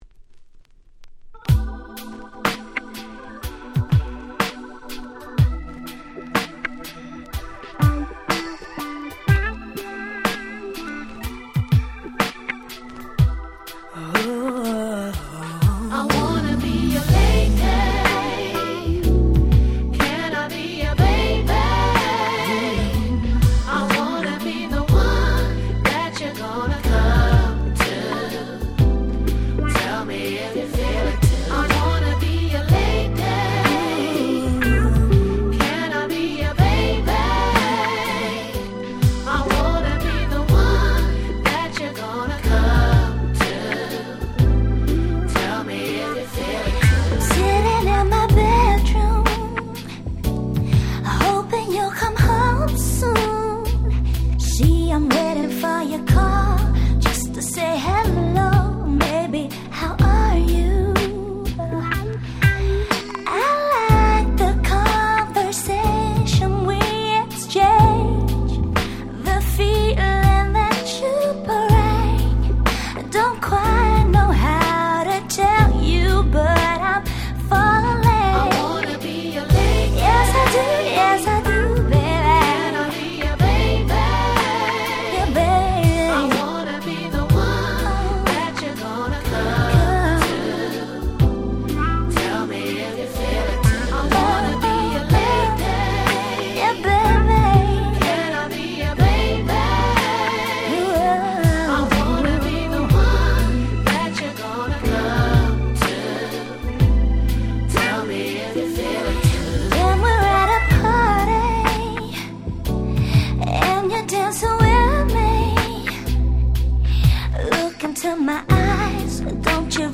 97' Smash Hit UK R&B / Slow Jam !!
美メロスロウジャムなAlbum Version